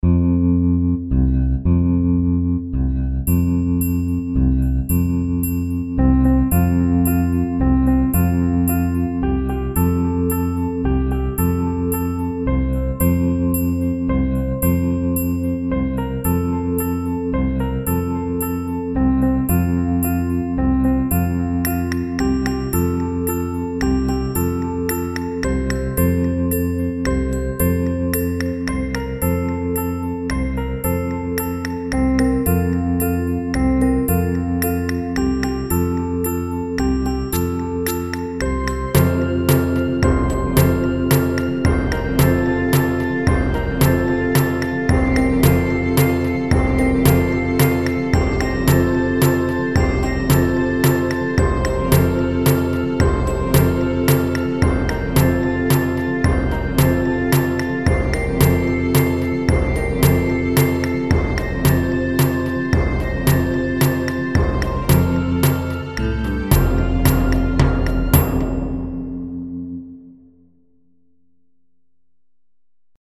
traditional English children’s round
Children’s song, England (U.K.)
karaoke